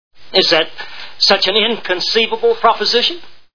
Deadwood TV Show Sound Bites